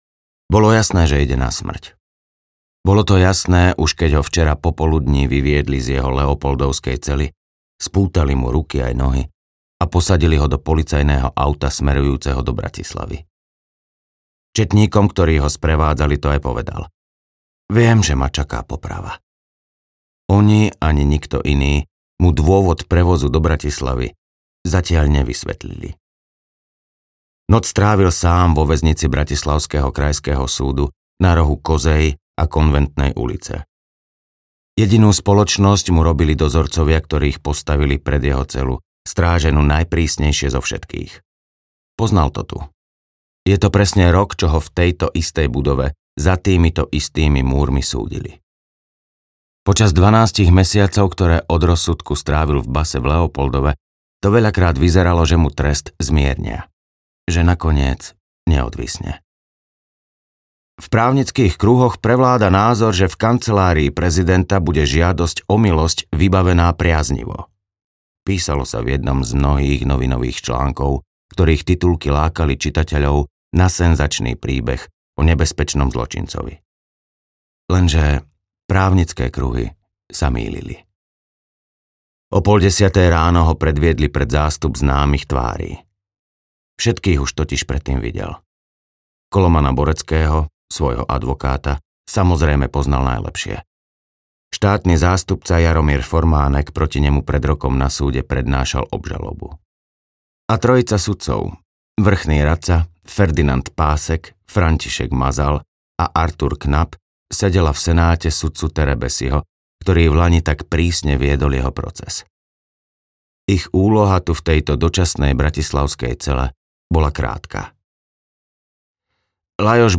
Neprišli sme vraždiť audiokniha
Ukázka z knihy
neprisli-sme-vrazdit-audiokniha